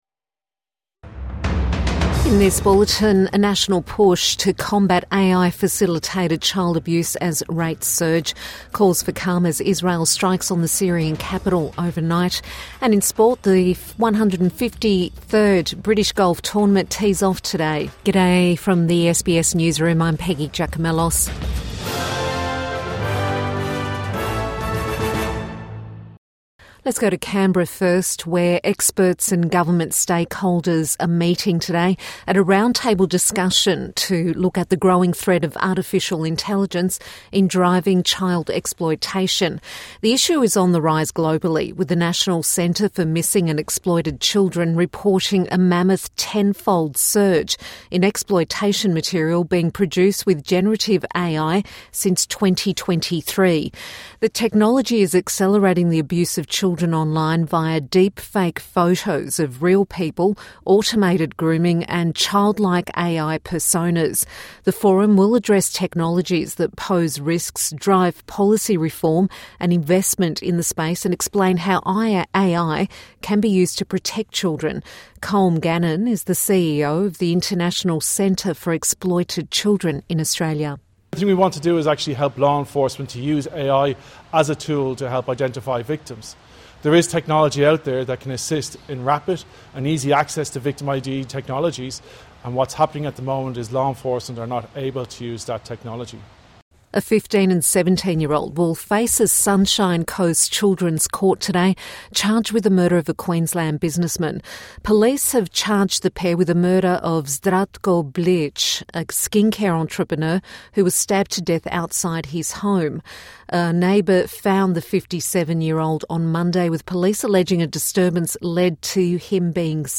A national push to combat AI facilitated child abuse | Midday News Bulletin 17 July 2025